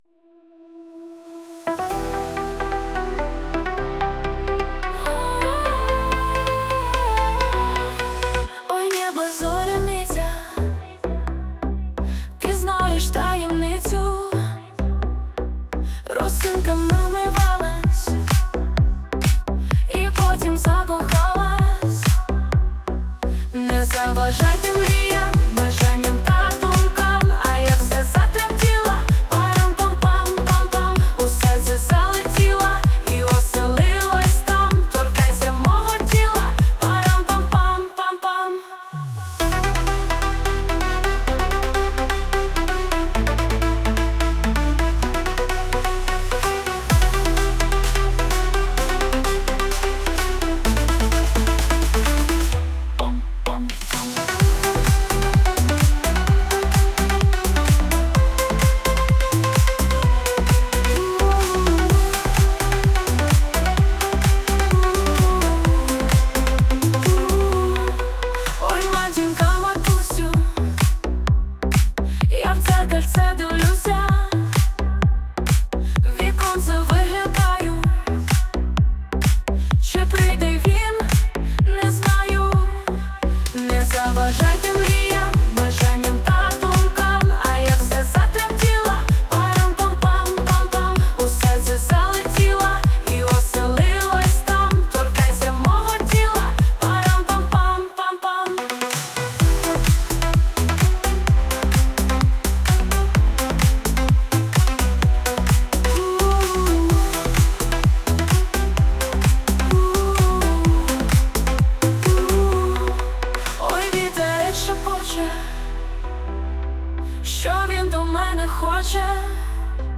Музика та голос =SUNO
СТИЛЬОВІ ЖАНРИ: Ліричний
ВИД ТВОРУ: Пісня
Грайлива, легка, танцювальна пісня 12 12 12